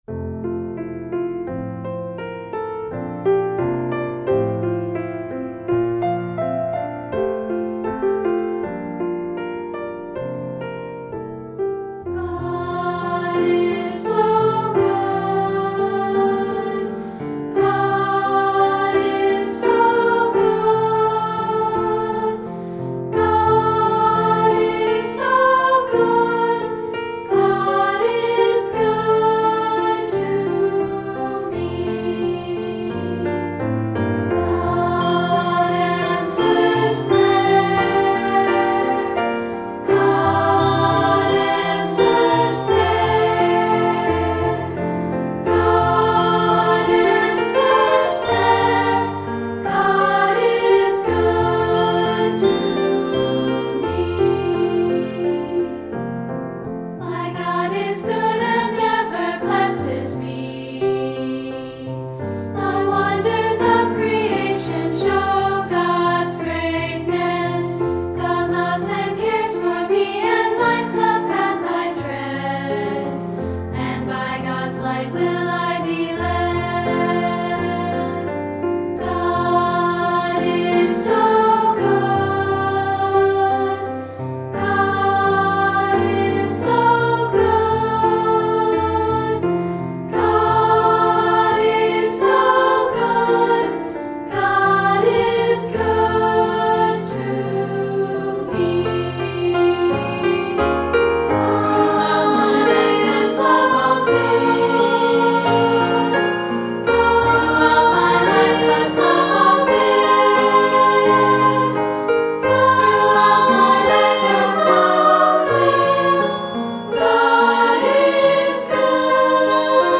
Voicing: Unison|2-Part